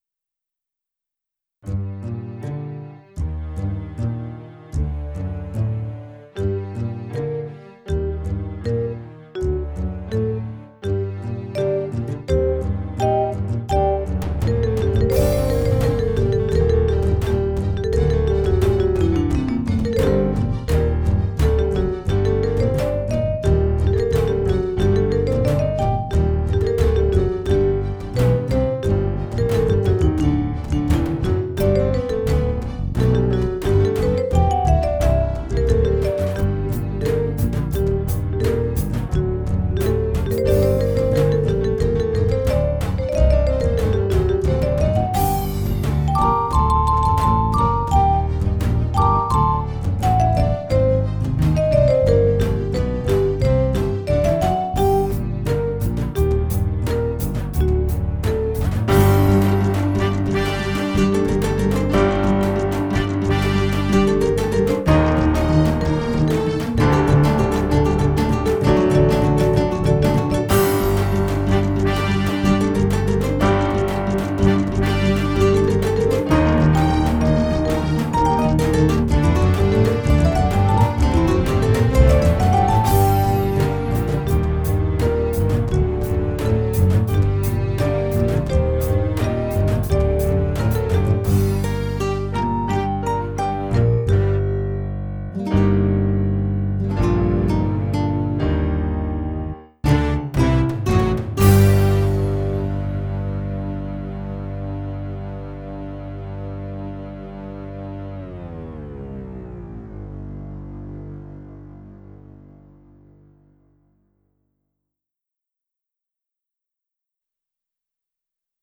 What is the reason of distortion in my songs? I do not understand
Hi guys, I need some tips from you: I have recorded these two instrumental songs (attached below) by using a Korg Krome workstation connected with two TRS cables to a Focus Scarlett 2i22 audio interface which in turn was connected to my laptop.
Despite the program did not detect any clipping, when I heard the mp3 files from VLC player in the both the songs I heard some distortion I did not succeed in deleting in any case.